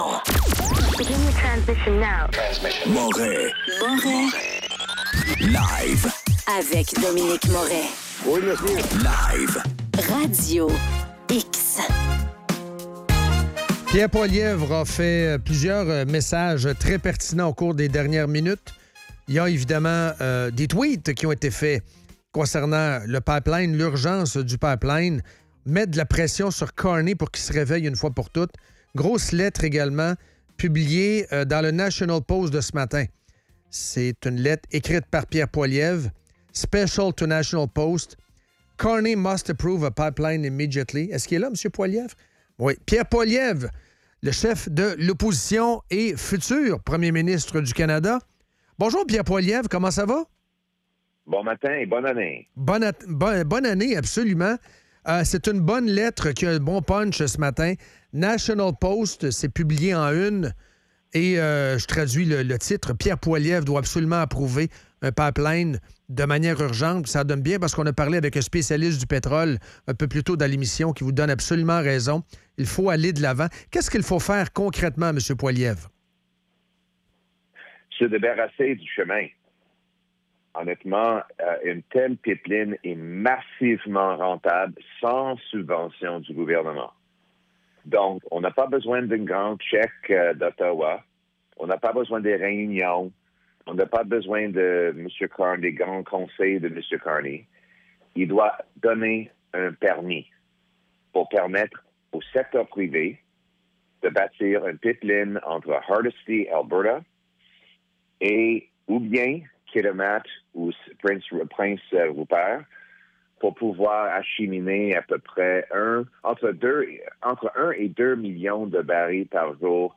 En entrevue, Pierre Poilievre.